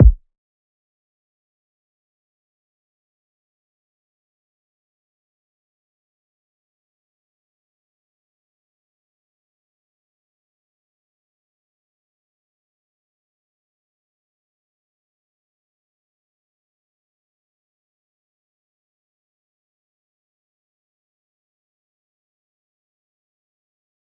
Rack Kick5.wav